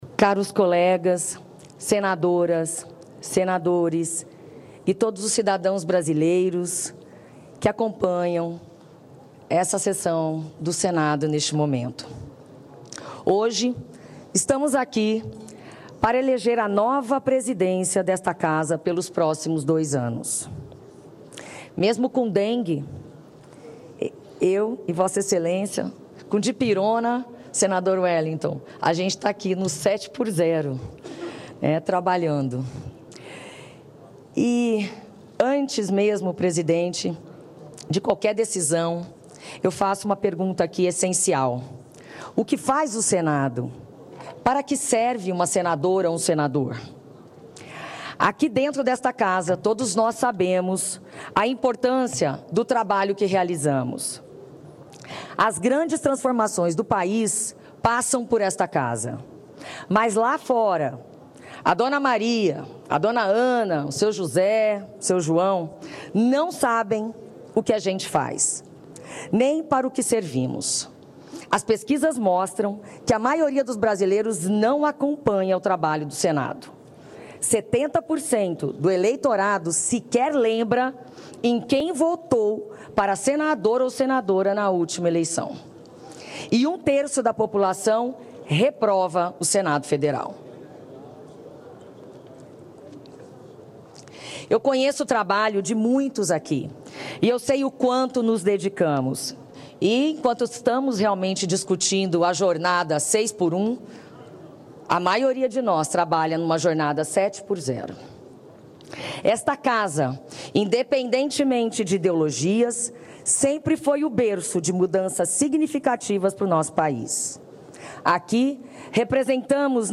Discurso da candidata Soraya Thronicke
A senadora Soraya Thronicke (Podemos-MS) apresenta suas propostas para presidir o Senado em discurso na reunião preparatória deste sábado (1º).